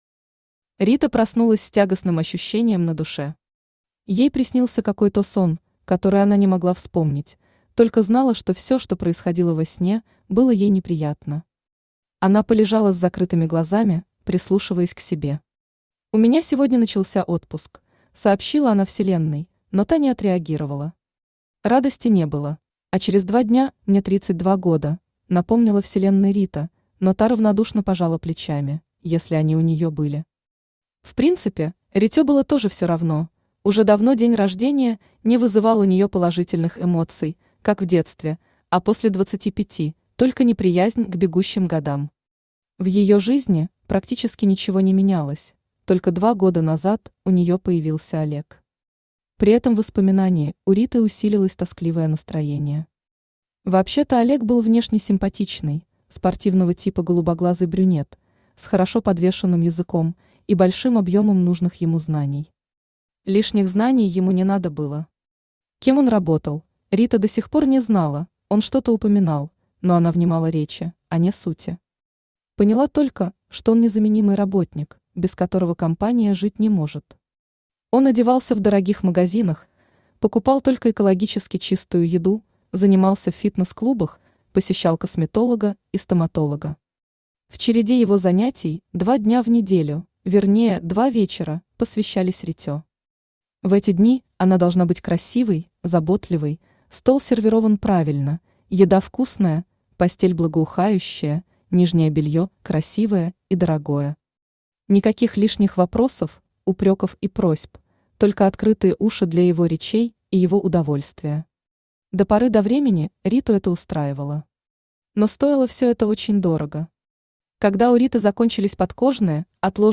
Аудиокнига Попроси у Луны | Библиотека аудиокниг
Aудиокнига Попроси у Луны Автор Светлана Петрищева Читает аудиокнигу Авточтец ЛитРес.